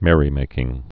(mĕrē-mākĭng)